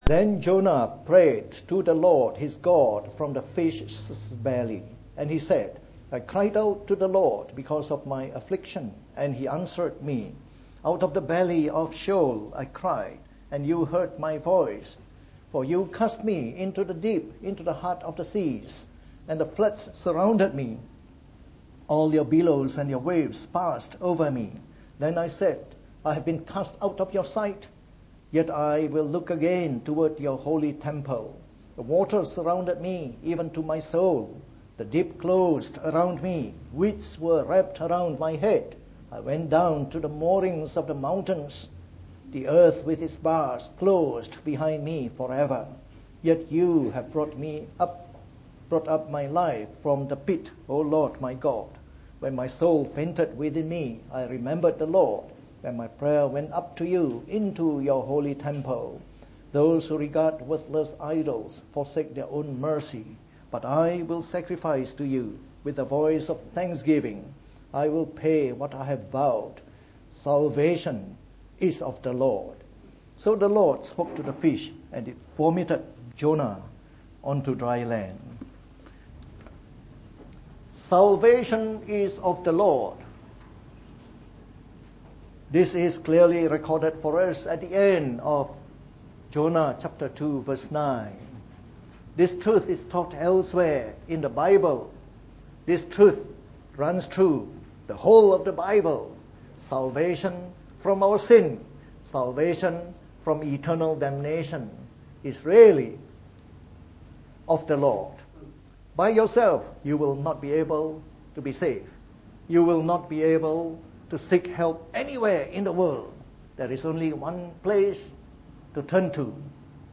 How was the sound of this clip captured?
From our series on the Book of Jonah delivered in the Morning Service.